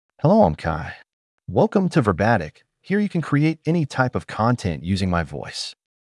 Kai is a male AI voice for English (United States).
Voice sample
Listen to Kai's male English voice.
Male
Kai delivers clear pronunciation with authentic United States English intonation, making your content sound professionally produced.